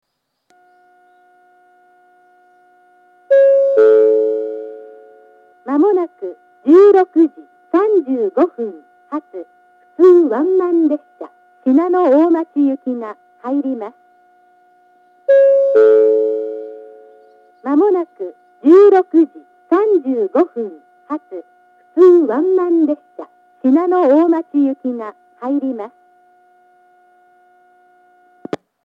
１番線上り接近予告放送 16:35発普通ワンマン信濃大町行の放送です。